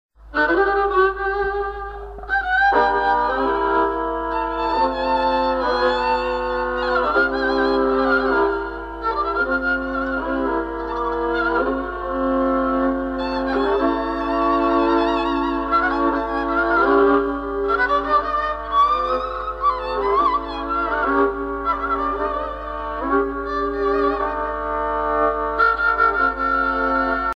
circonstance : funérailles
Pièce musicale éditée